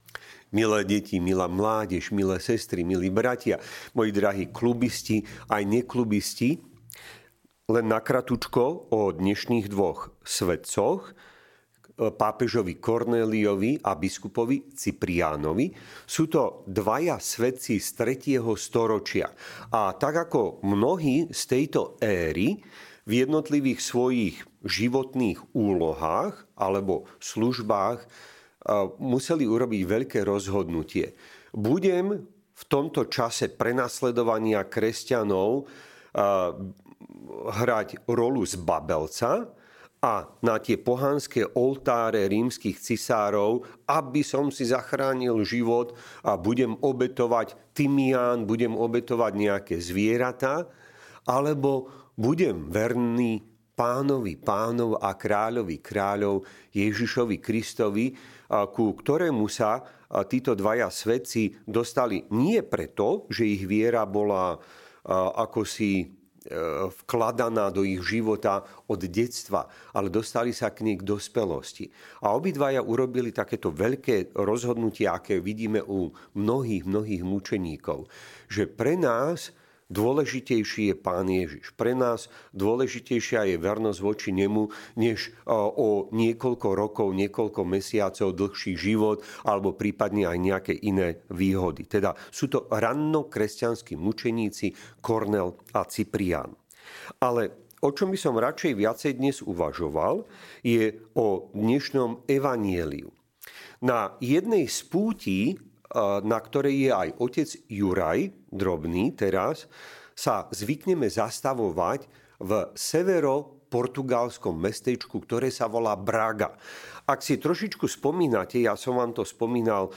Kázne